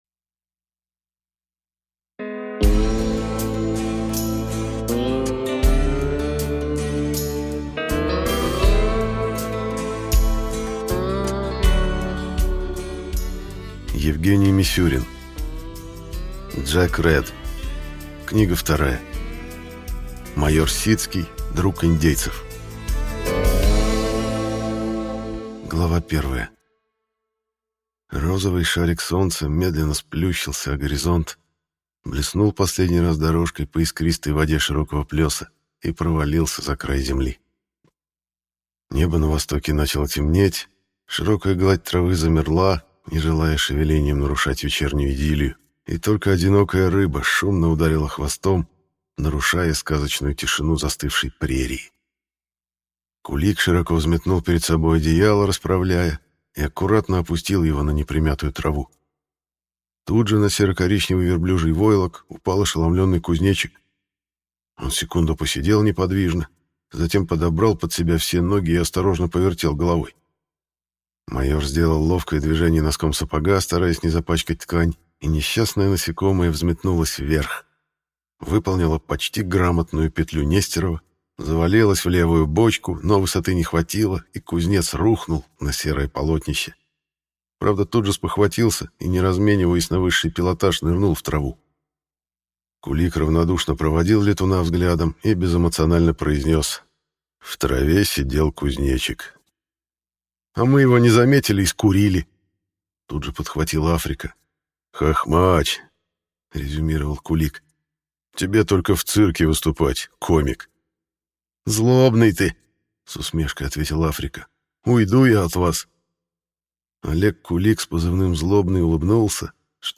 Аудиокнига Майор Сицкий – друг индейцев | Библиотека аудиокниг